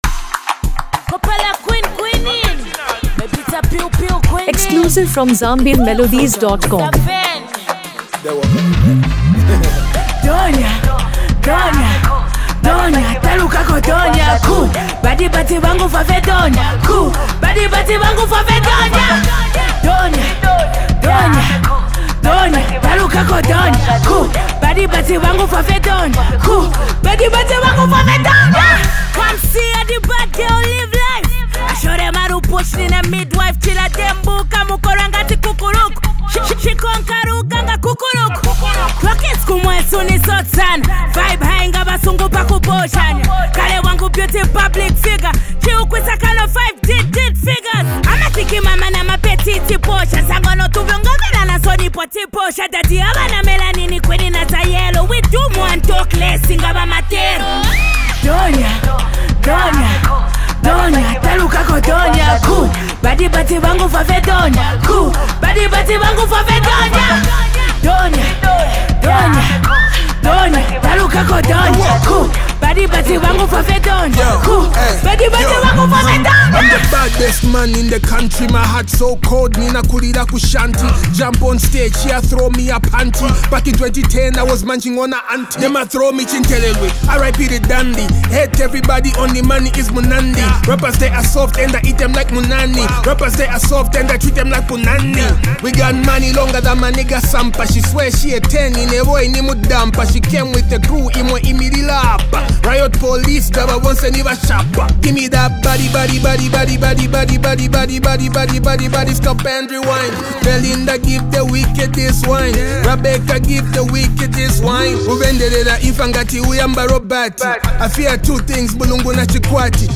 Latest Club Banger Download in Zambia
” delivering catchy flows and vibrant beats.
Genre: Dancehall